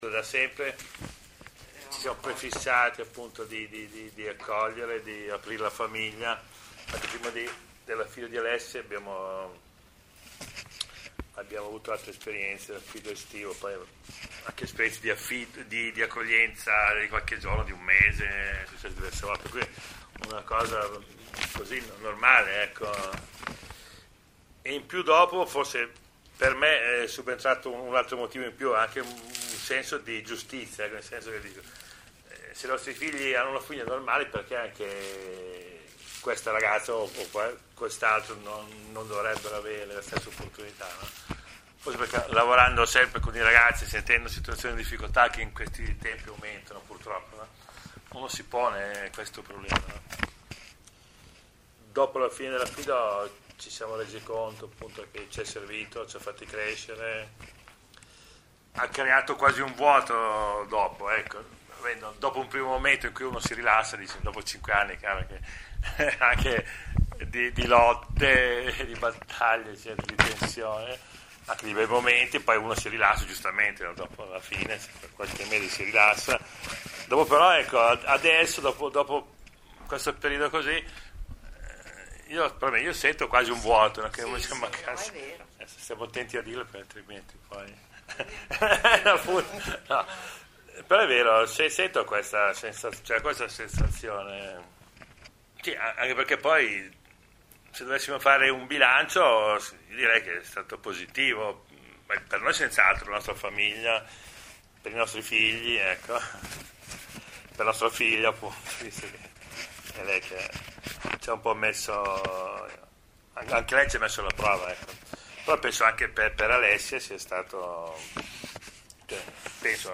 - Testimonianze audio di famiglie affidatarie: 1  |
testimonianza_affido_3.mp3